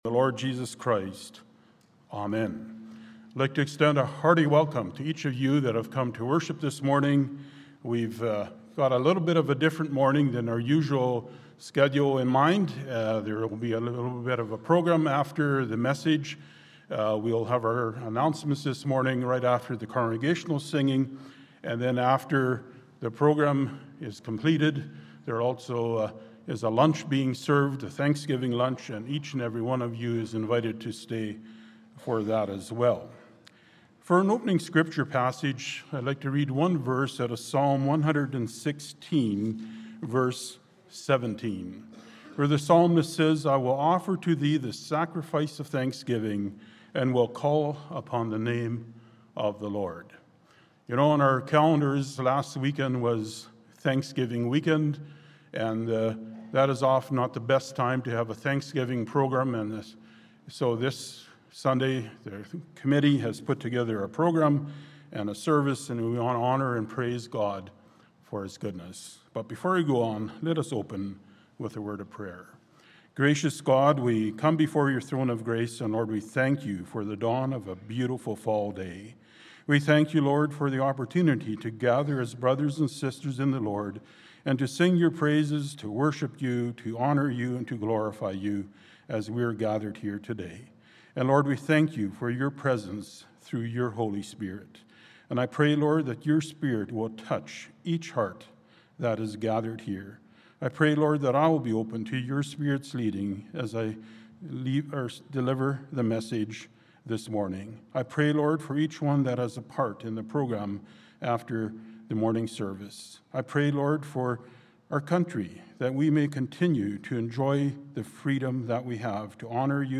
Thanksgiving Service/Program